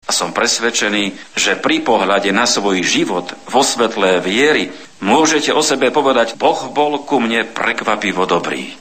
Levočská pouť vyvrcholila slavnostní mší svatou
V  kázání vyzdvihl Mons. Bober Mariin postoj děkování za milosrdenství, které Bůh vykonal v celých dějinách spásy.